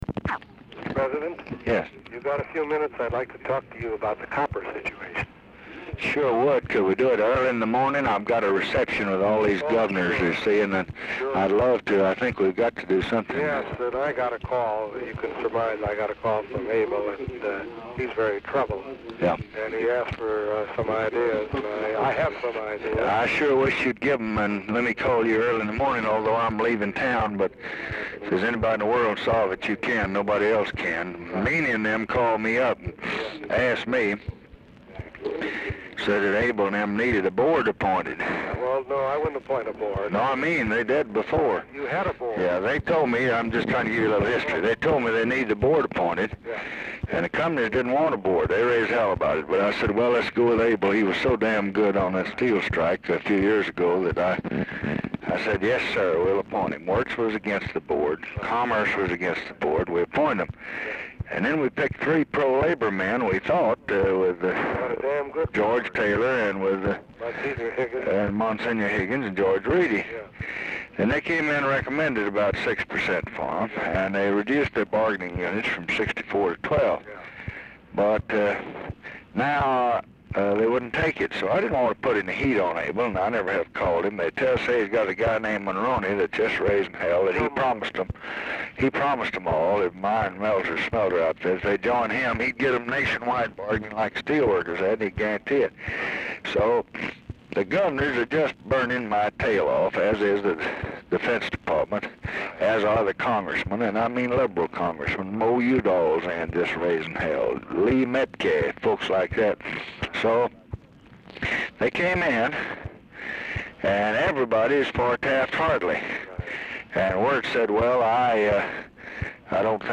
Telephone conversation # 12736, sound recording, LBJ and ARTHUR GOLDBERG, 2/29/1968, 7:28PM | Discover LBJ
Format Dictation belt
Location Of Speaker 1 Mansion, White House, Washington, DC